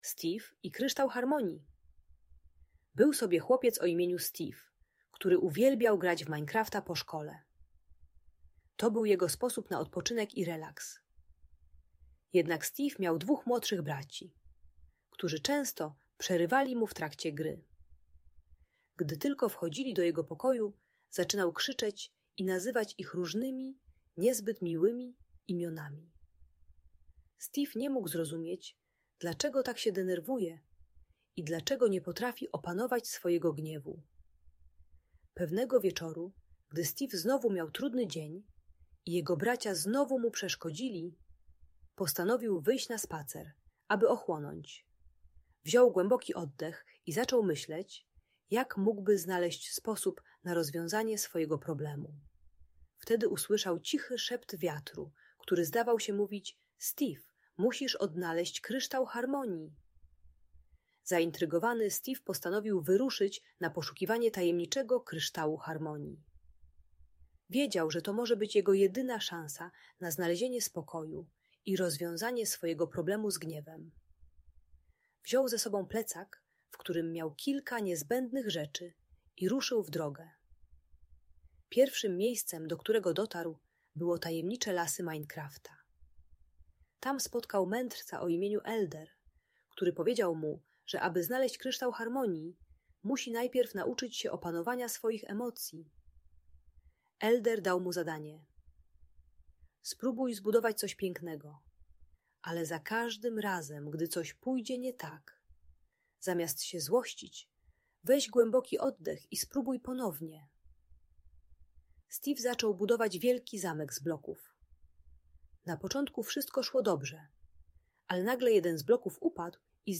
Audiobajka w świecie Minecrafta uczy techniki głębokiego oddychania, współpracy z rodzeństwem oraz przepraszania i wybaczania.